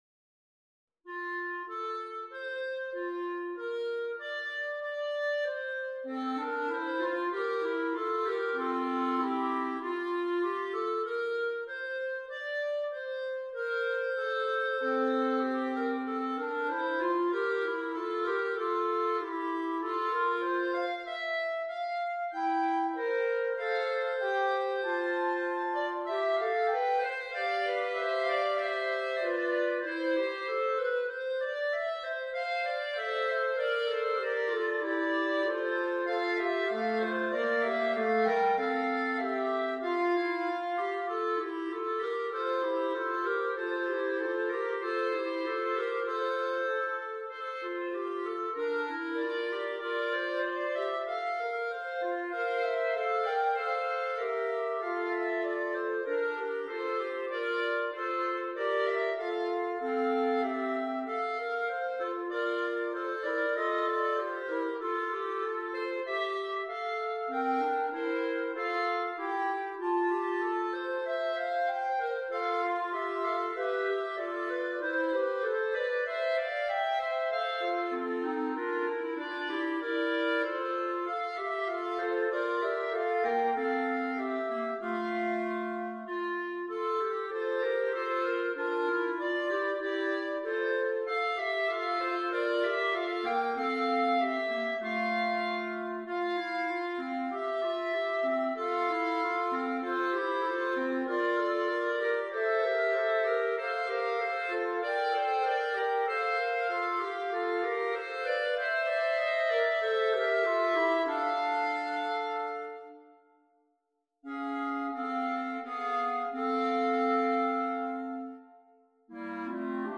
The great Easter hymn tune
arranged for three clarinets.